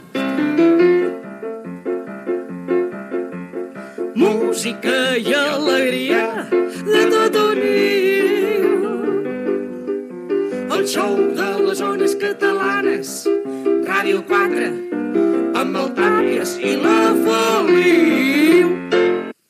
Careta cantada del programa
Entreteniment